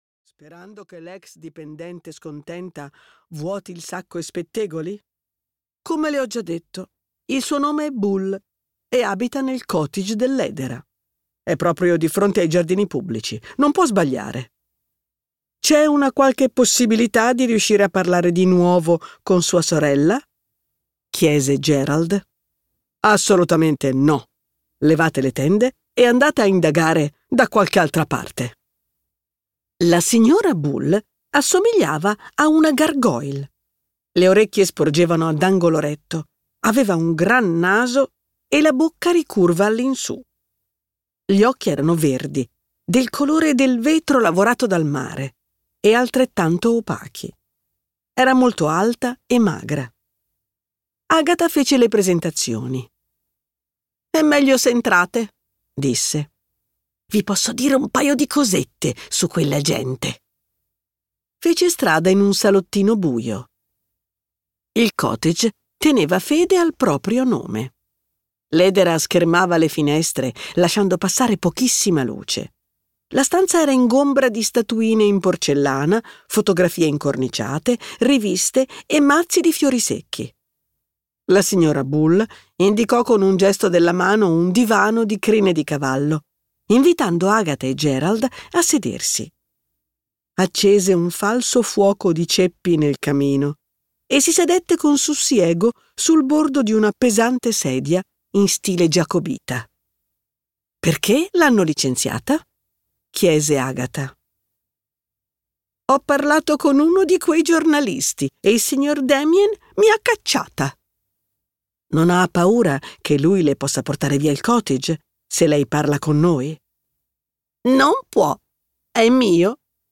Morti e sepolti" di M.C. Beaton - Audiolibro digitale - AUDIOLIBRI LIQUIDI - Il Libraio